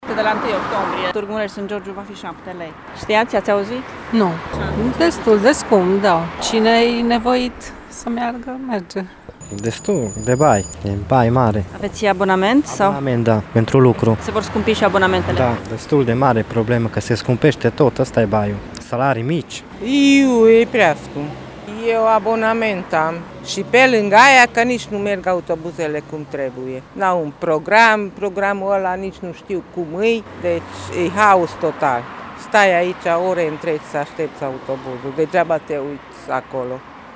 Târgumureșenii sunt îngrijorați de creșterile permanente de tarife la produse și servicii, dar sunt nemulțumiți și de calitatea transportului local: